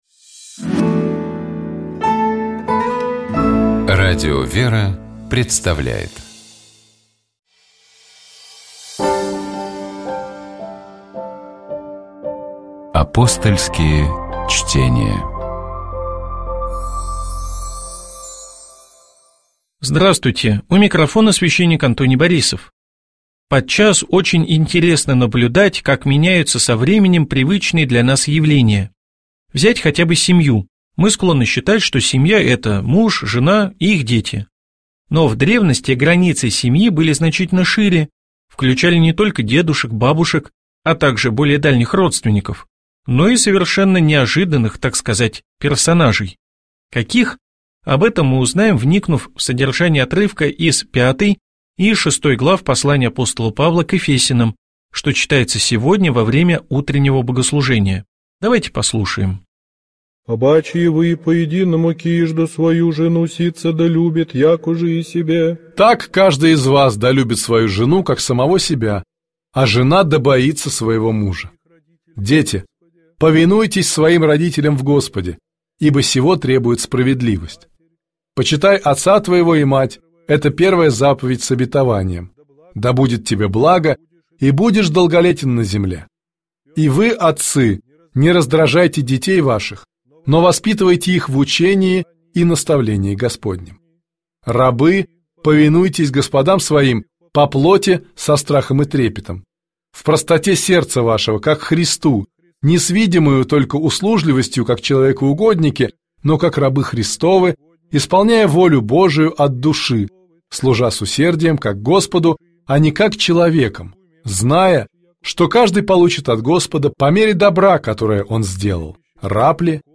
Богослужебные чтения Скачать 07.12.2025 Поделиться Жизнь наша состоит из успехов и неприятностей.